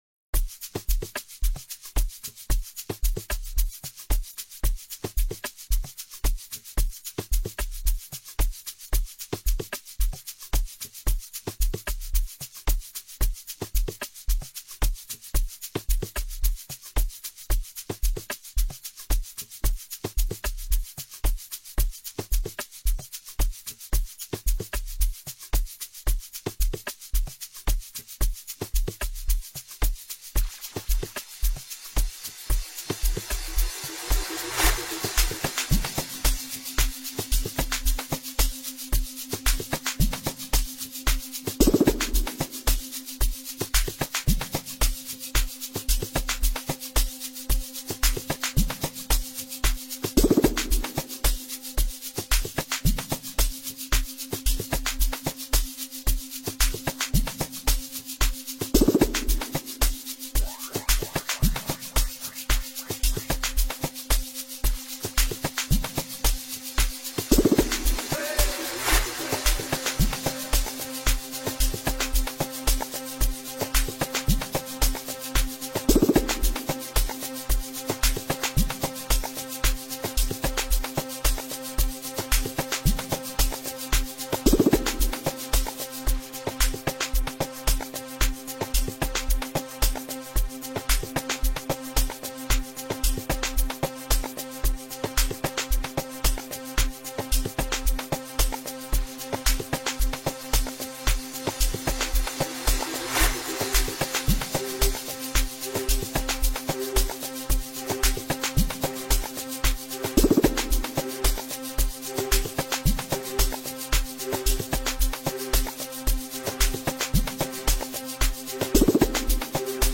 piano scene